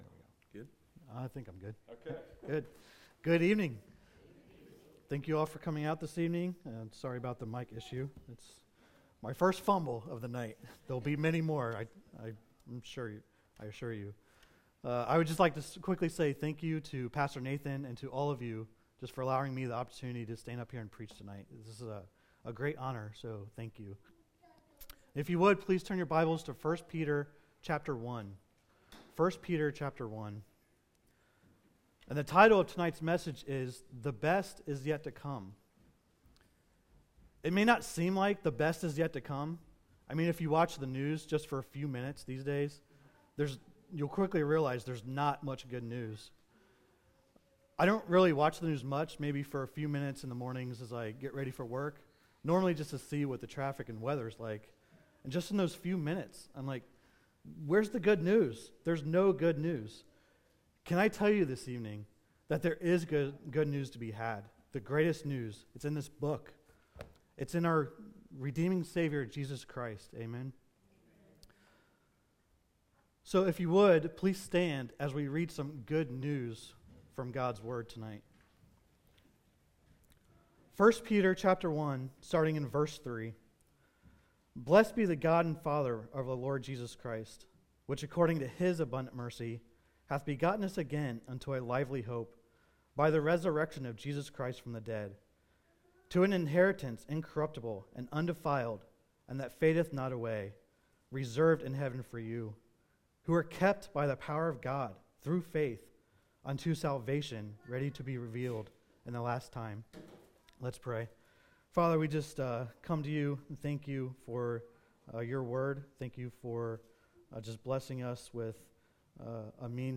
Sunday evening, March 2020.